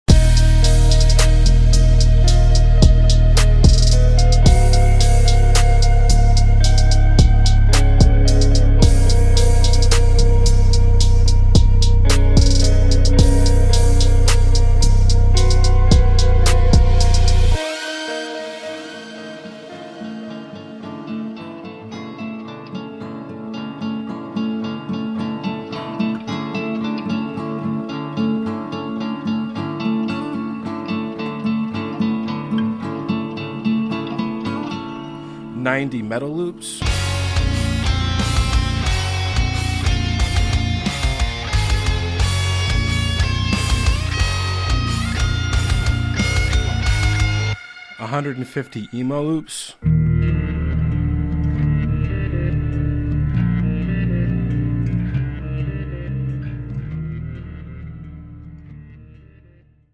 Live Sounds